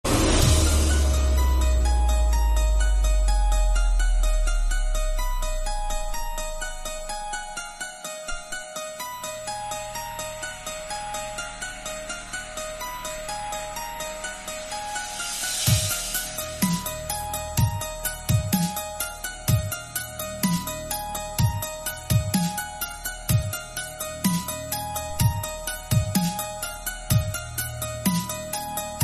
Play, download and share Música sinistra original sound button!!!!
musica-sinistra.mp3